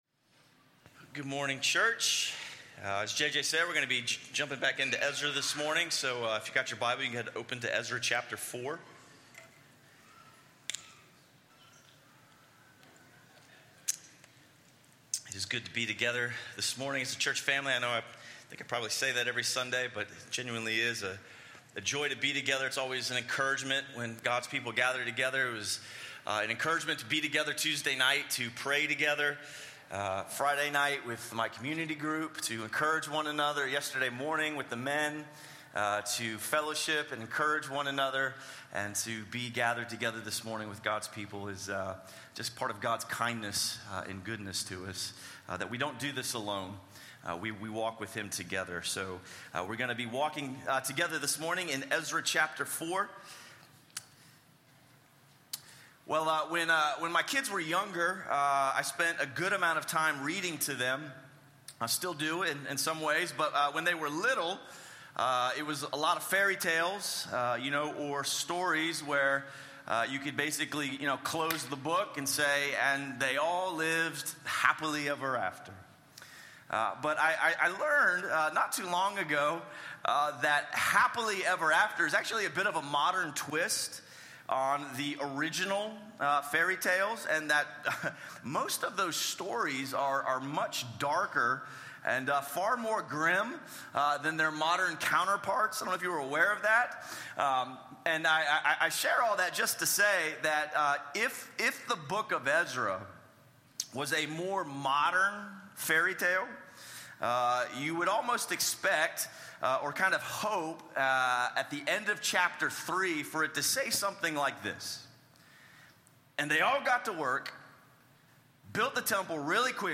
A sermon series through the books of Ezra and Nehemiah.